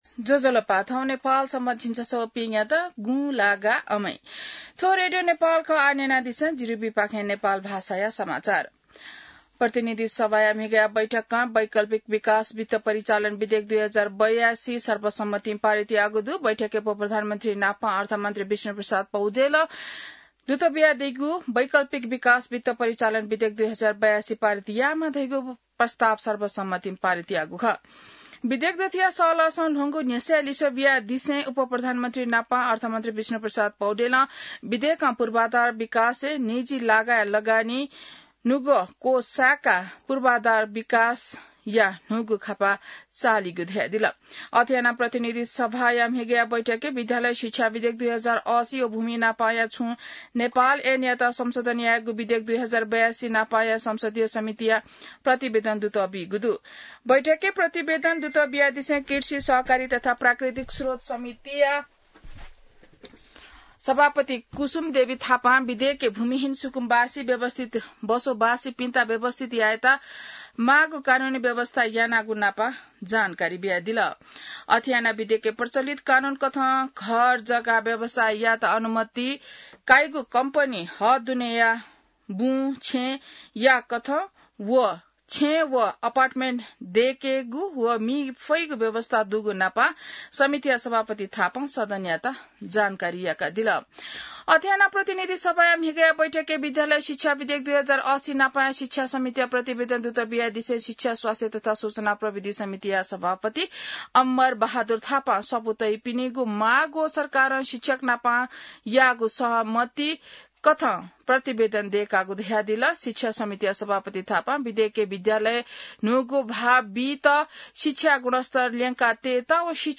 An online outlet of Nepal's national radio broadcaster
नेपाल भाषामा समाचार : ७ भदौ , २०८२